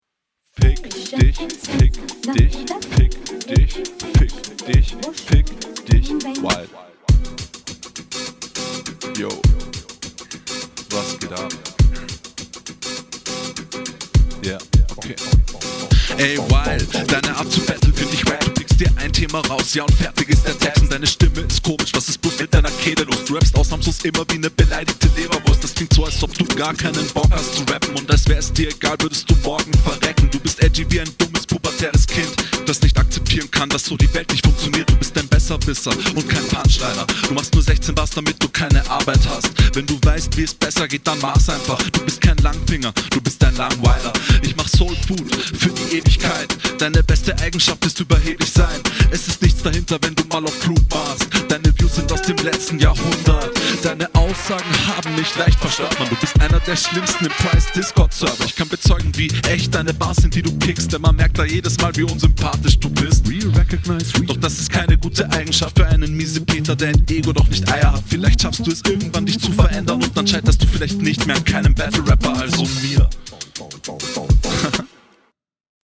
Uh ich finde die Mische hier ganz weird, iwie ist die stimme so isoliert überm …
ok beat hat mich anfang kurz verstört aber intro lustig. flow auf dem beat hat …